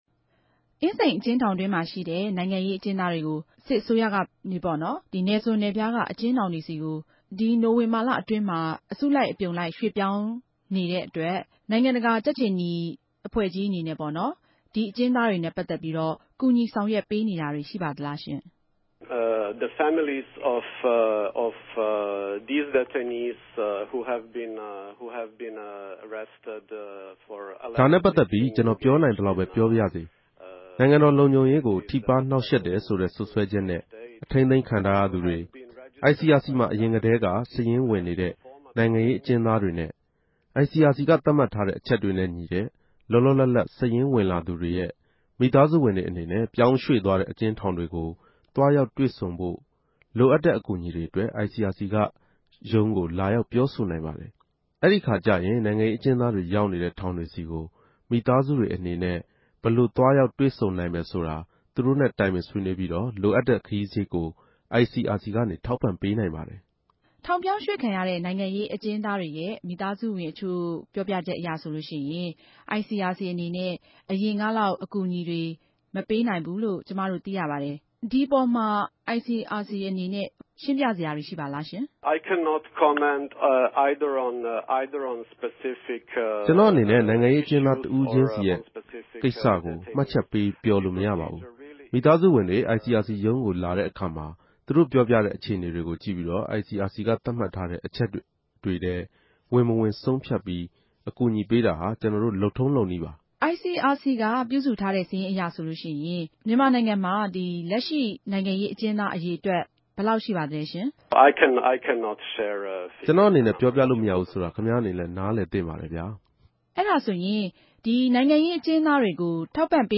ိံိုင်ငံတကာ ုကက်ေူခနီံြင့် ဆက်သြယ်မေးူမန်းခဵက်။